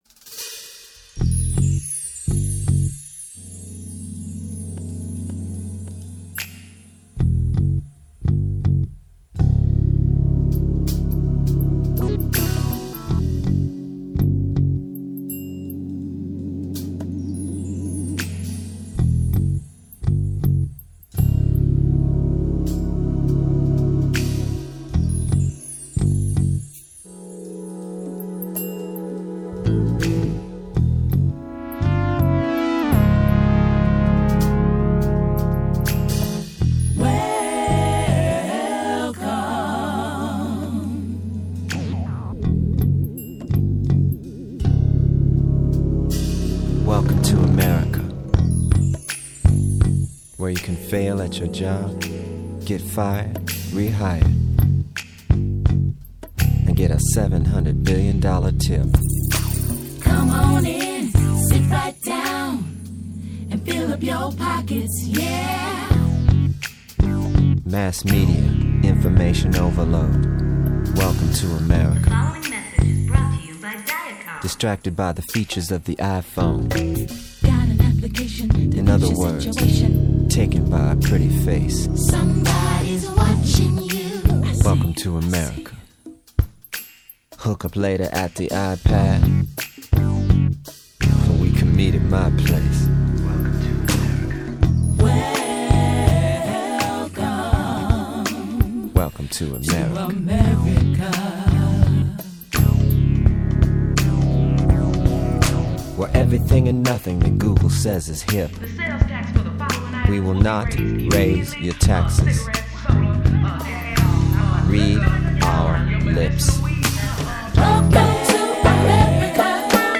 Pop, Funk, Rock